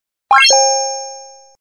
礼物UI弹窗2.MP3